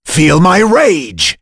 Dimael-Vox_Skill1.wav